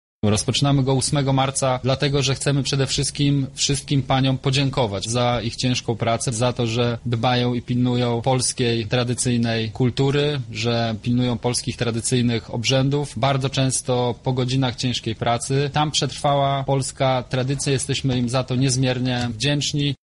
Dzięki nim kwitnie także kultura kulinarna – mówi Michał Mulawa, wicemarszałek województwa lubelskiego.